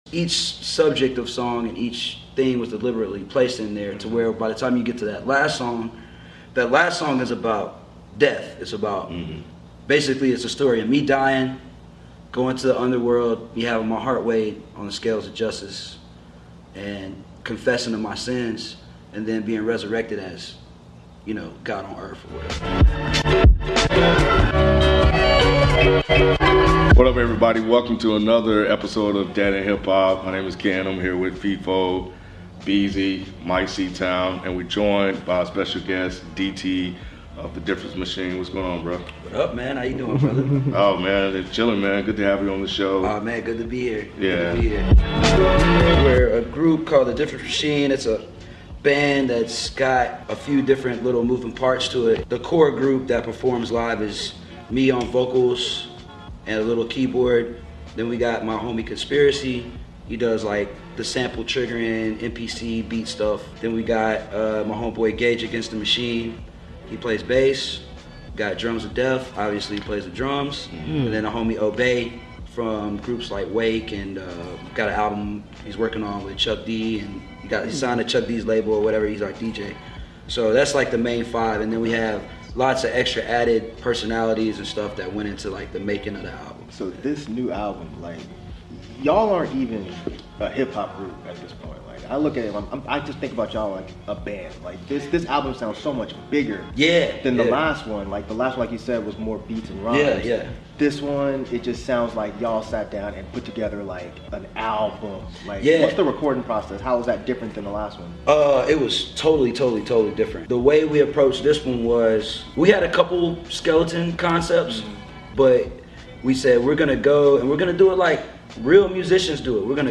DEHH Interview